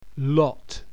Many American accents also pronounce PALM, LOT and THOUGHT the same:
British English